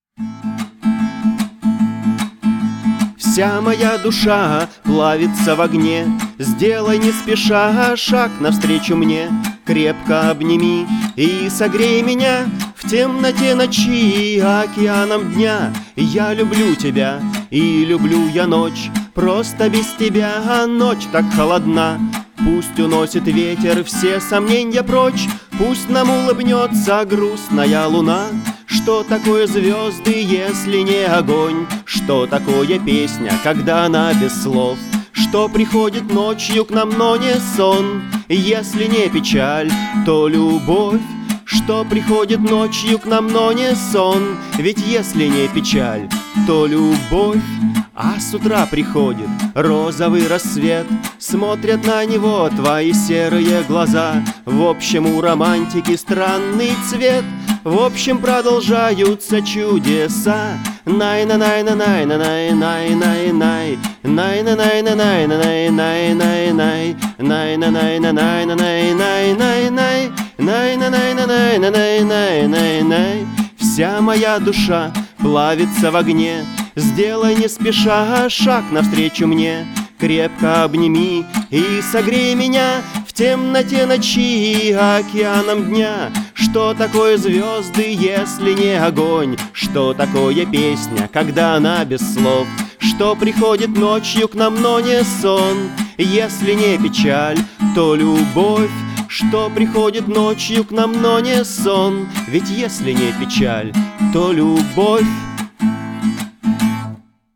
политический панк-рок
Просто под гитару, без аранжировки.